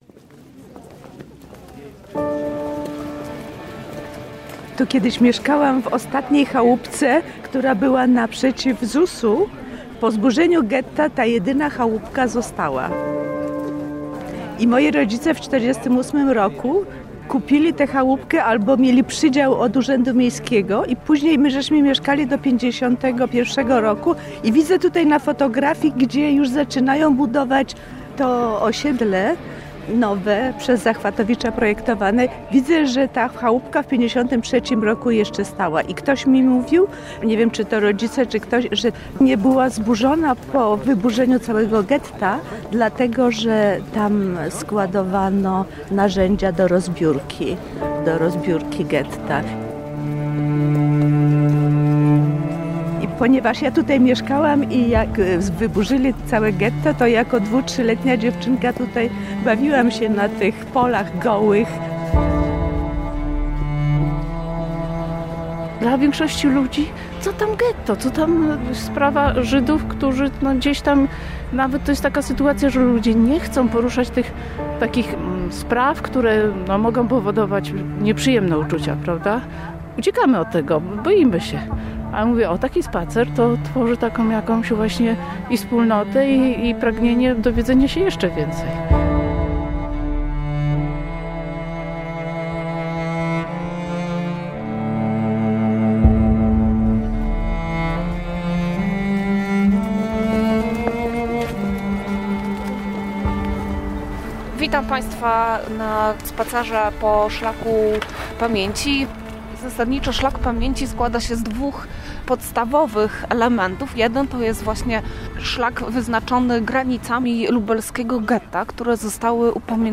Fot. archiwum Szlakiem pamięci Tagi: reportaż